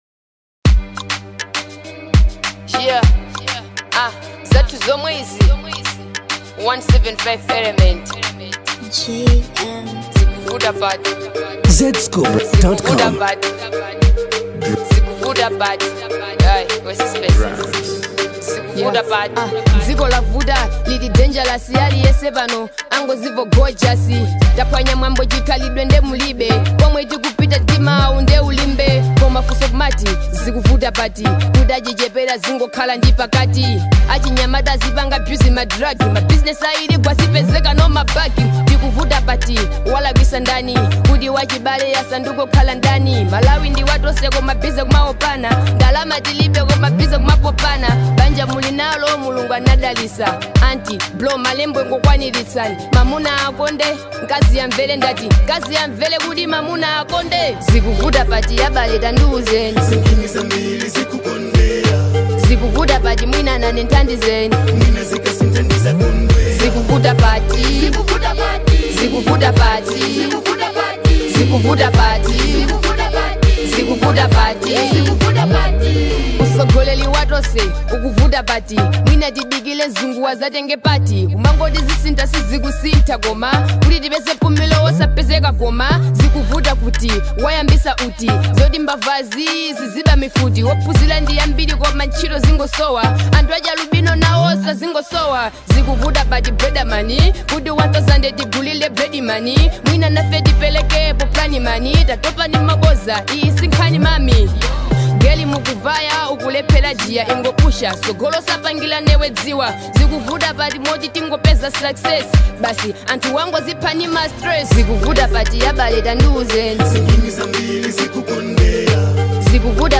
African Mix
Malawian female rapper
hip hop